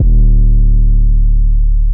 808 [ Mafia ].wav